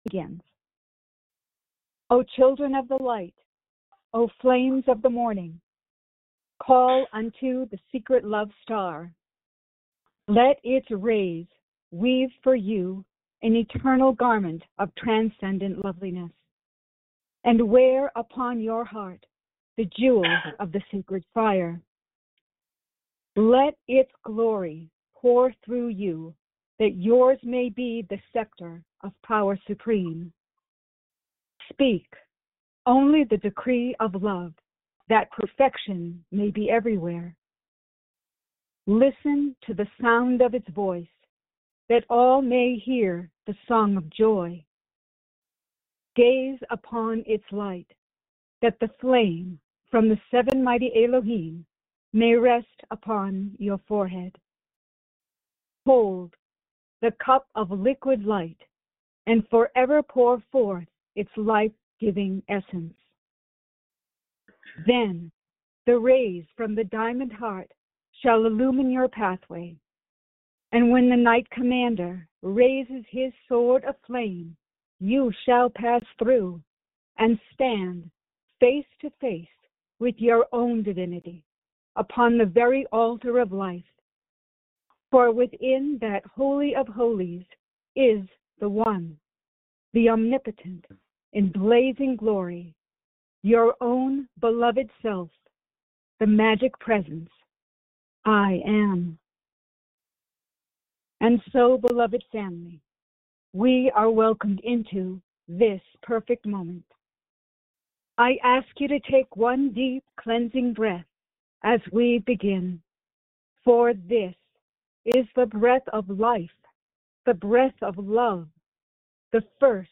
Meditation
Follow along group meditation